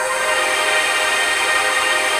ATMOPAD06 -LR.wav